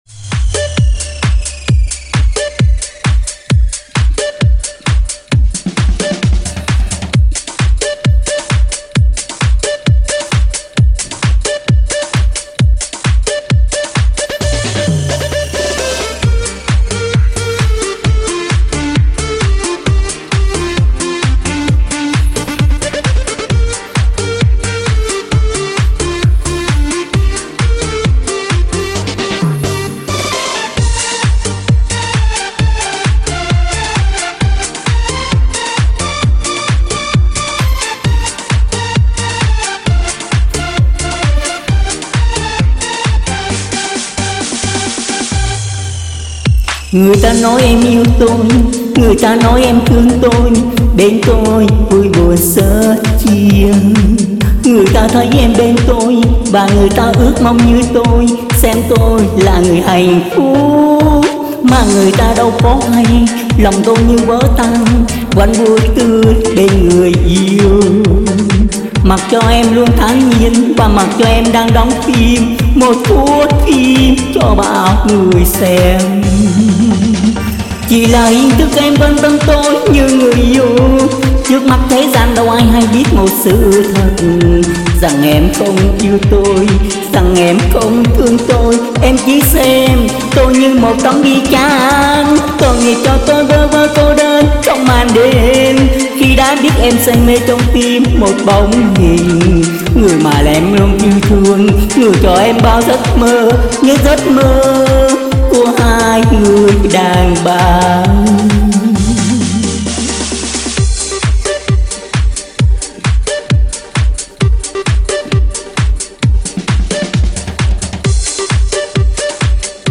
Chúc cho các bạn nghe nhạc remix vui vẻ nha .